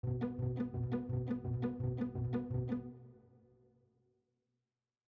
RythmGame/SimpleGame/media/chords/variation1/B.mp3 at 785453b009a8a8e0f5aa8fdd36df7abdc11622fa